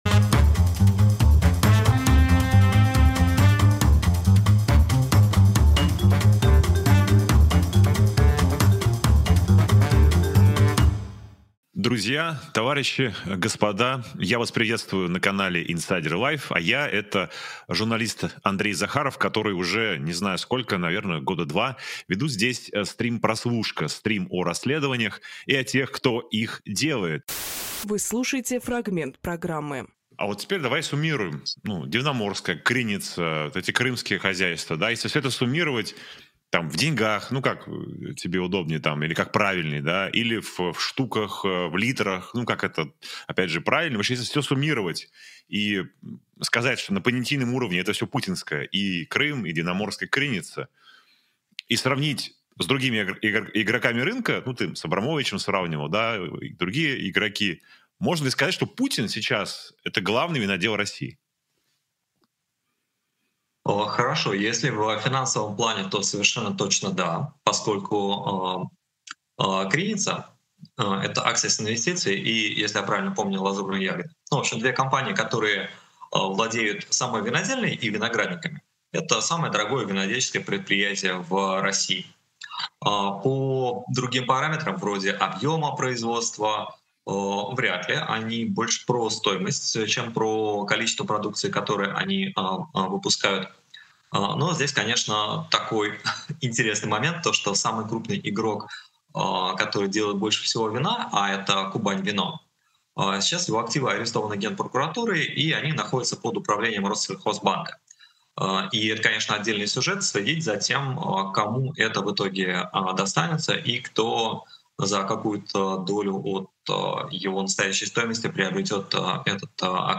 Фрагмент эфира от 08.10.25